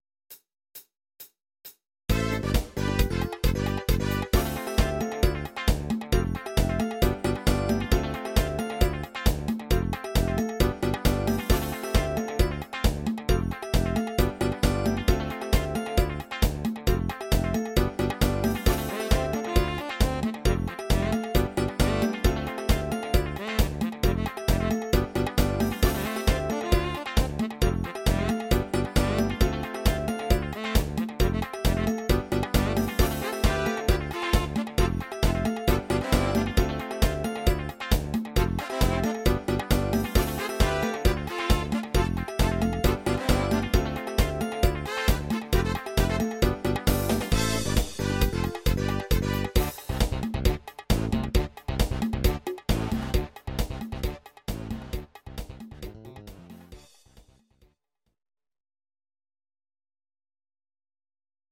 Audio Recordings based on Midi-files
Pop, Disco, 1970s